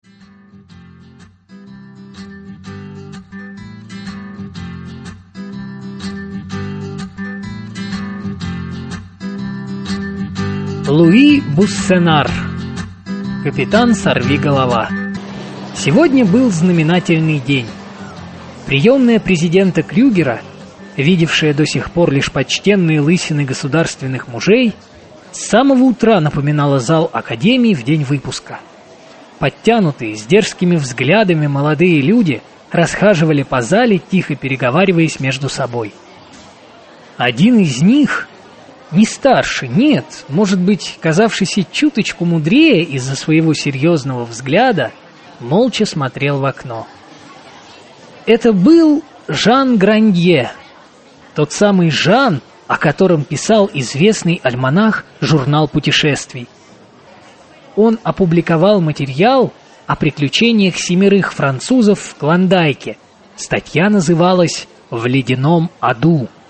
Аудиокнига Капитан Сорви-голова | Библиотека аудиокниг
Прослушать и бесплатно скачать фрагмент аудиокниги